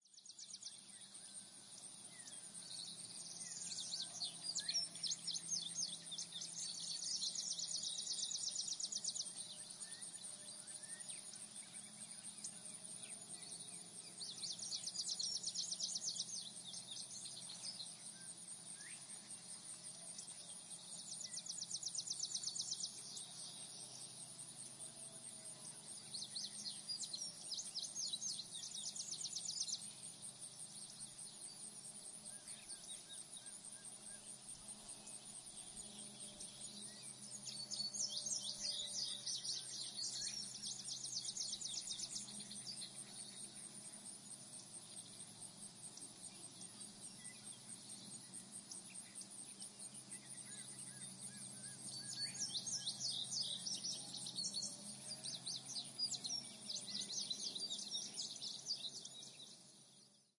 Звуки поля
Здесь собраны аудиозаписи, которые передают бескрайние просторы, шелест колосьев, щебетание жаворонков и другие звуки сельской идиллии.
Утренние звуки русской природы